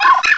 cry_not_riolu.aif